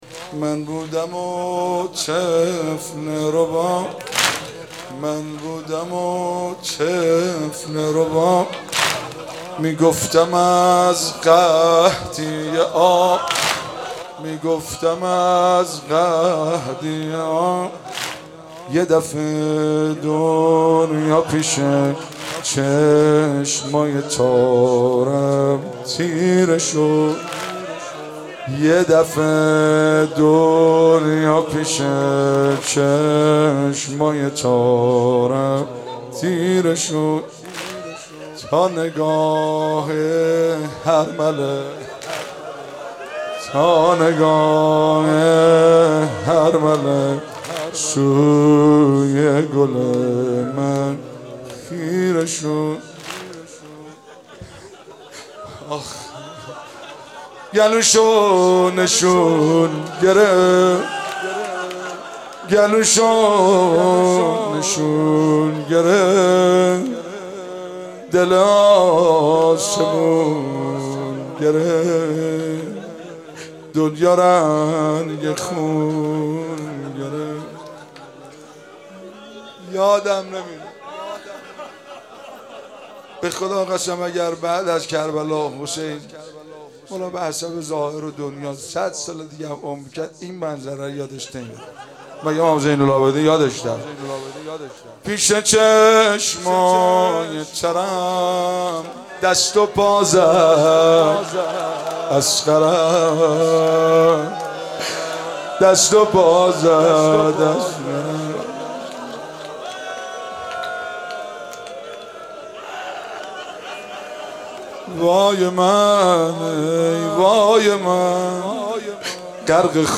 شب هفتم محرم95/هیئت فاطمیون قم(مسجد مقدس جمکران)
واحد سنگین/من بودم و طفل رباب